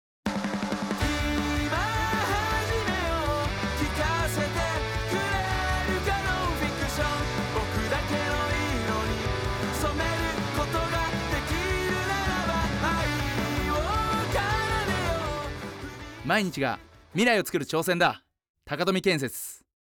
※CMにて使用させてもらった曲はDDND（ダダンダン）さんの「あお」と「ノンフィクション」の2曲になります。
・CM「ノンフィクション」バージョン
DDND_NF_Radio_CM_02.wav